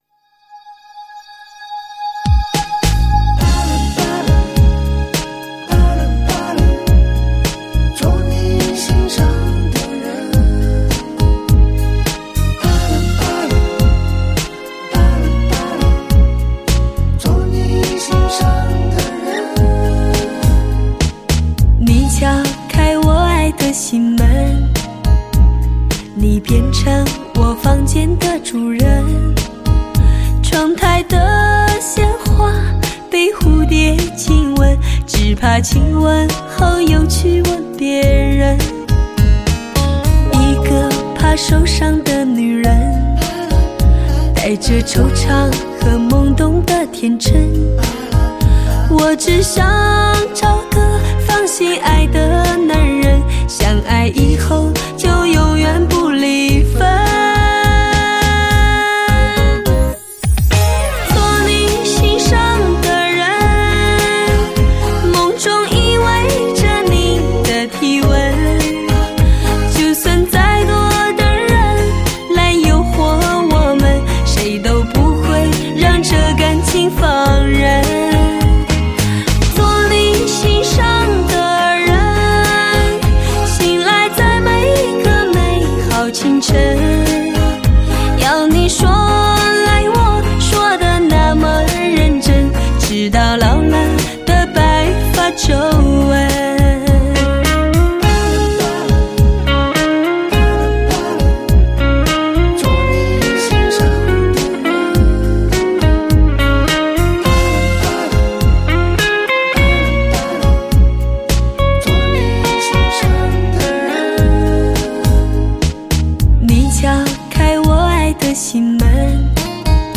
绝色女声
魅力之曲，发烧靓碟，极具诱惑的绝色女声，撩动人心的优美旋律，
每一曲音色都妩媚多姿好，绝对值得你一听再听，体验前所未有的感受。